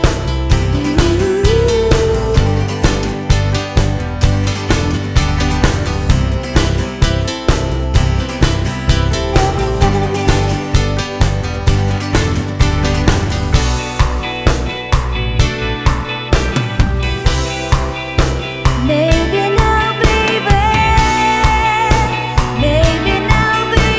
For Solo Male Pop (1980s) 4:14 Buy £1.50